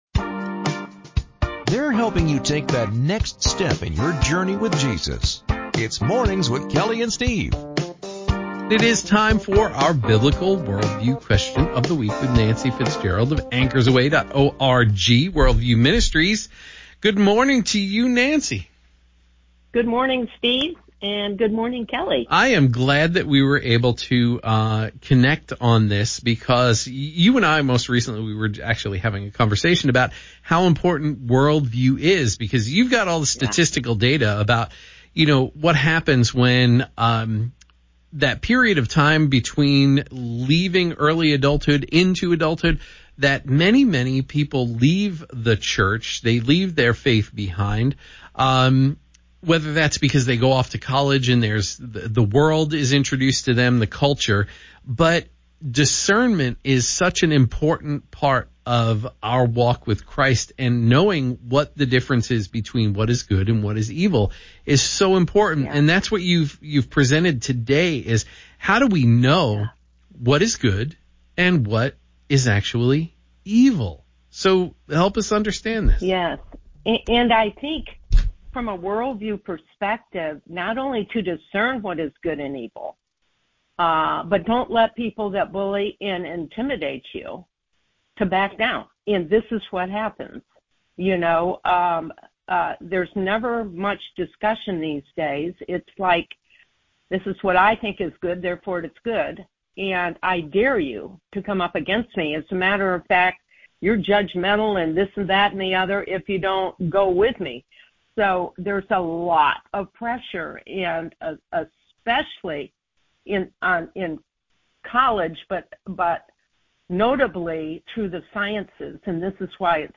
answer this question on Moody Radio